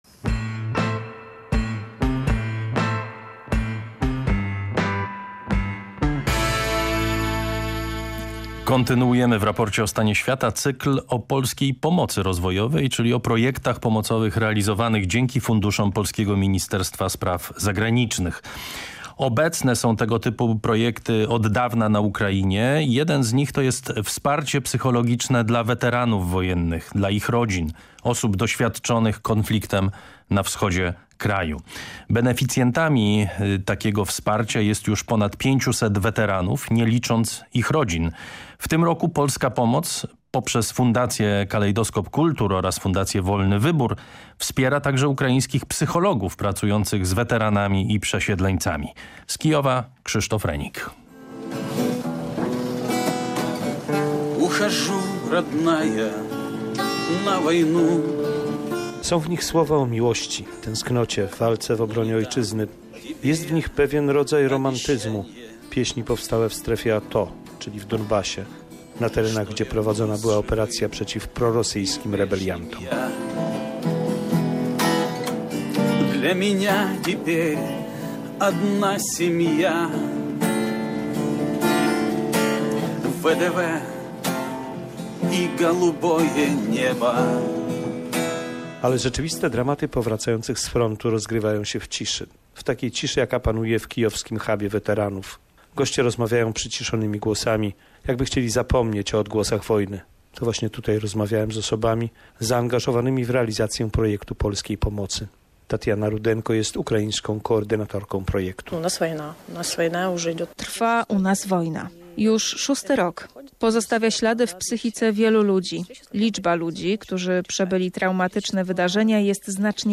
Reportaż oraz fotoleracja na stronach Raportu o stanie świata Programu Trzeciego PR